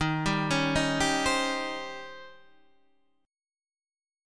Eb13 chord